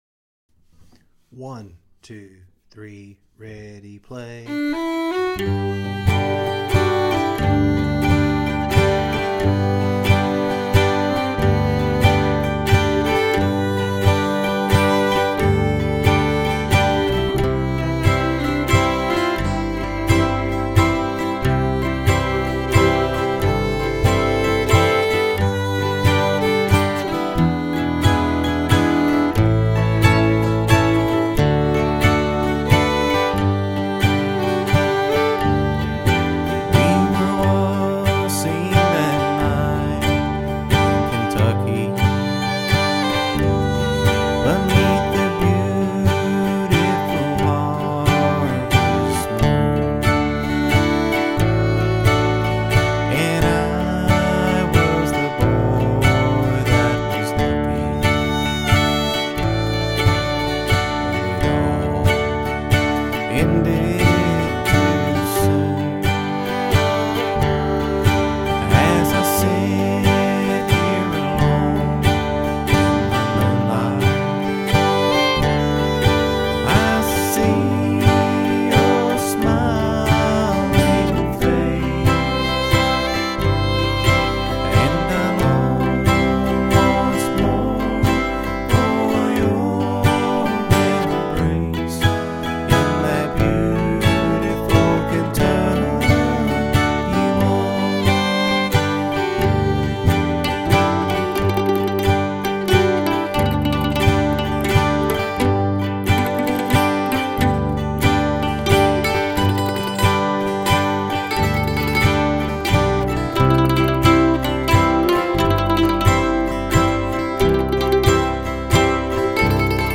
Kentucky-Waltz-Key-G.mp3